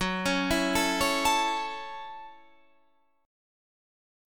Listen to F#m7 strummed